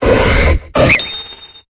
Cri de Gallame dans Pokémon Épée et Bouclier.